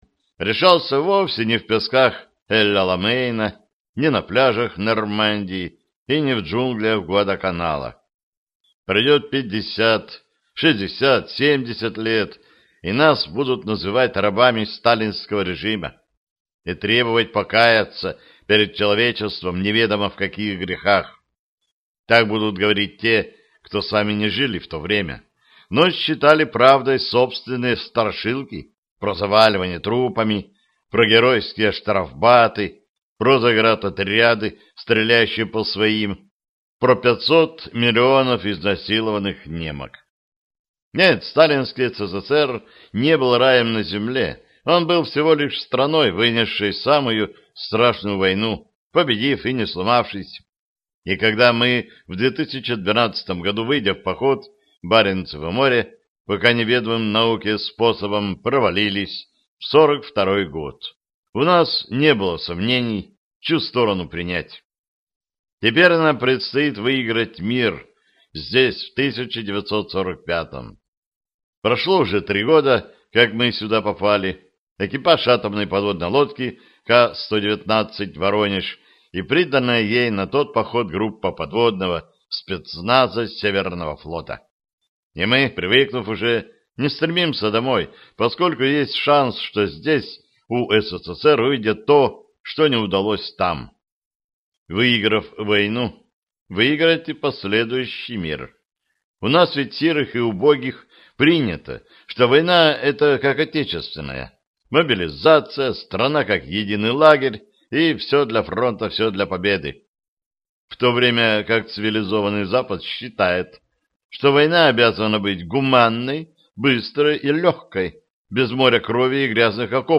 Аудиокнига Красный тайфун (сборник) | Библиотека аудиокниг